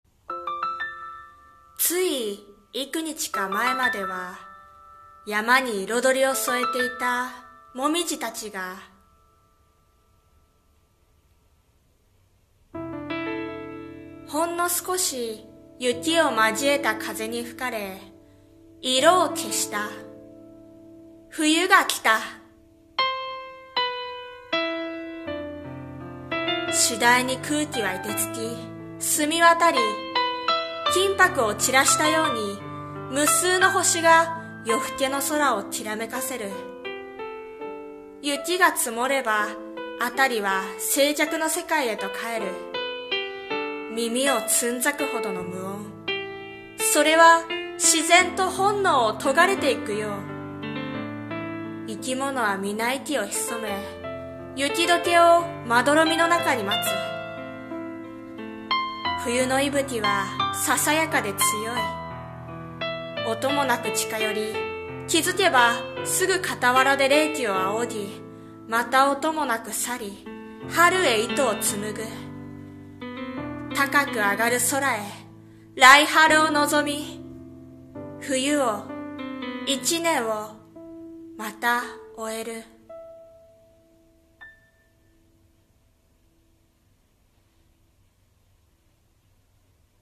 さんの投稿した曲一覧 を表示 一年過ぎる 【朗読】【和風】【一人声劇】